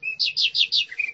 SFX_Bird_1.ogg